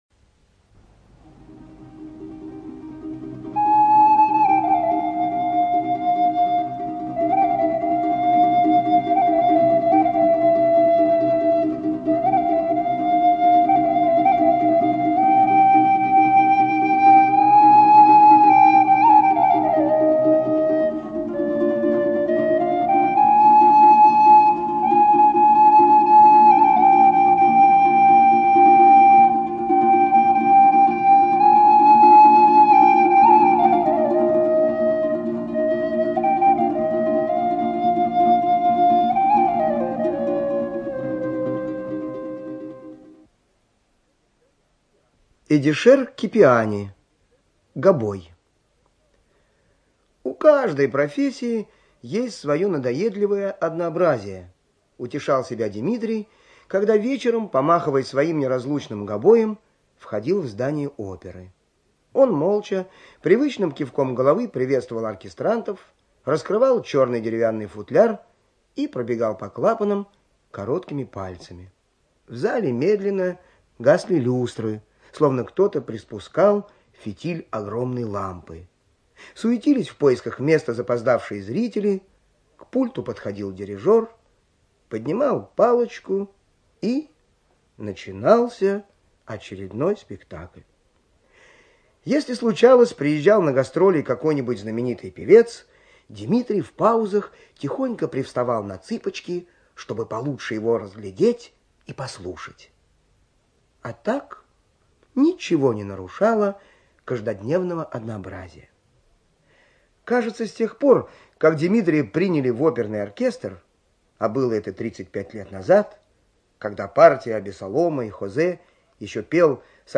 ЧитаетДуров Л.